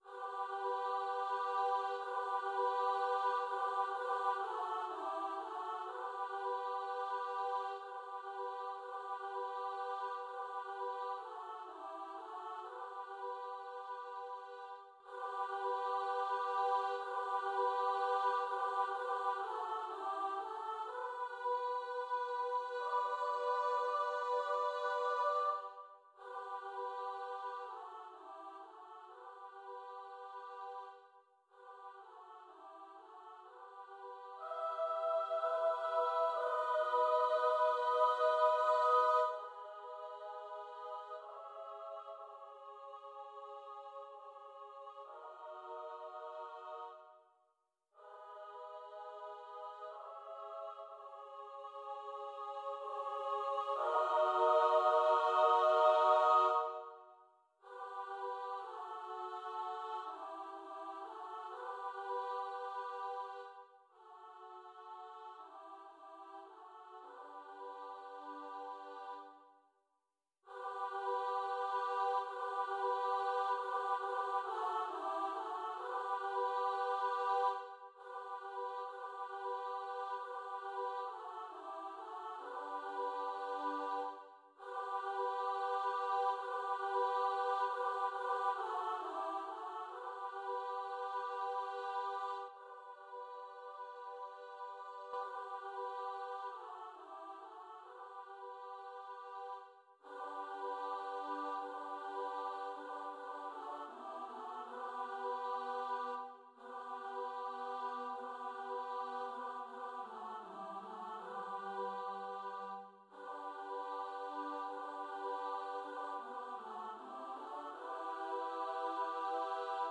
A Christmas lullaby.